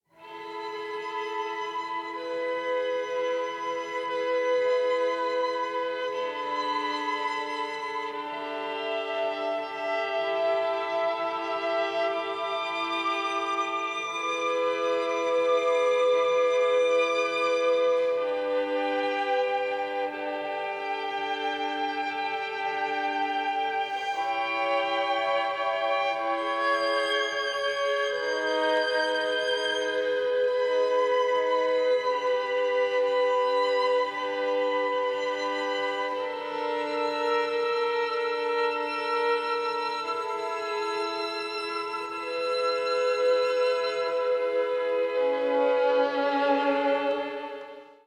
violin
viola
cello
version for string quartet